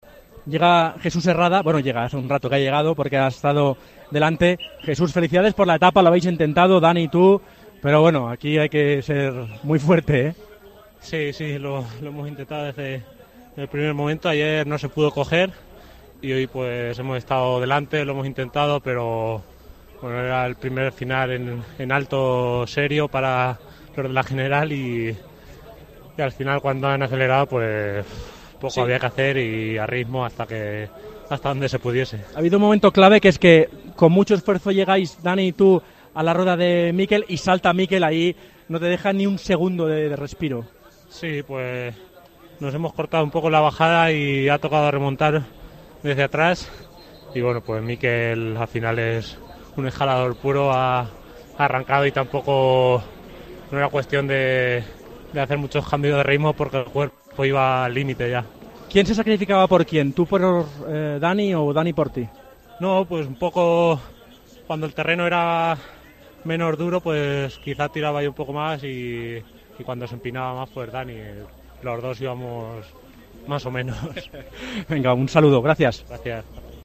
Jesús Herrada habló para el micrófono de COPE sobre el intento por ganar la etapa y lamentó que al "quedarnos cortados en el descenso" perdieron opciones al triunfo.